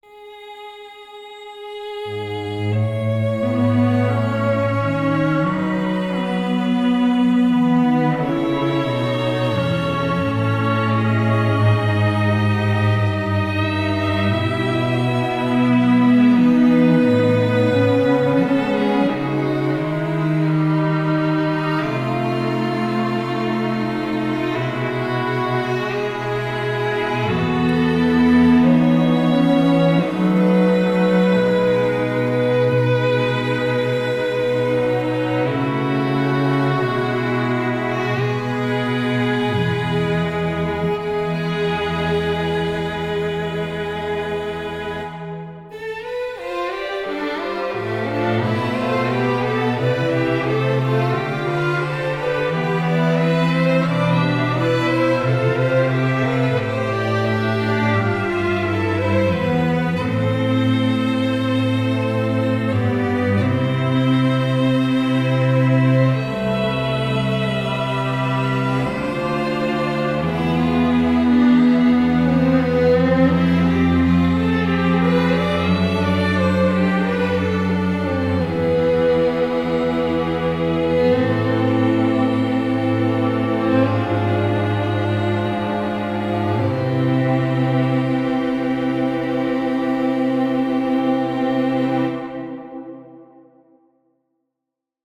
For Strings
Return-To-Sender-String-Quartet.mp3